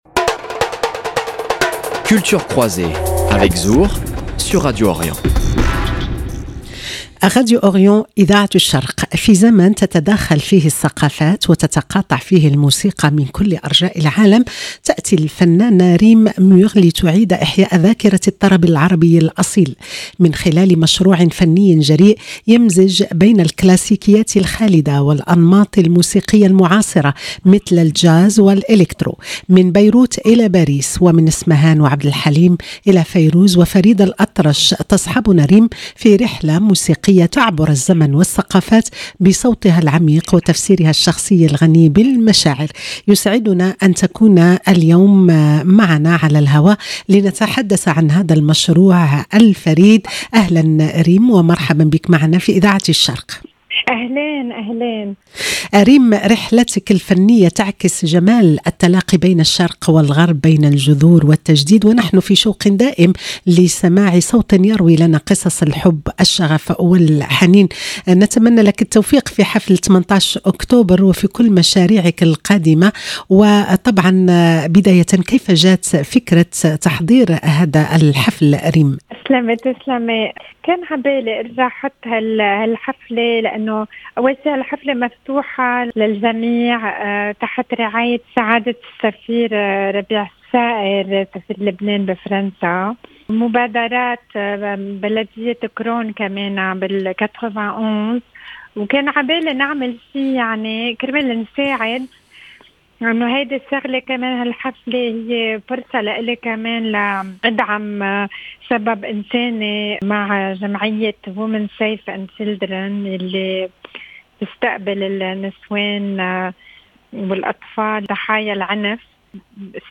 يسعدنا اليوم أن نلتقي بها لنتحدث عن هذا المشروع الفريد، عن مسيرتها، عن شغفها بالفن، وعن رؤيتها لمستقبل الأغنية العربية في الغرب.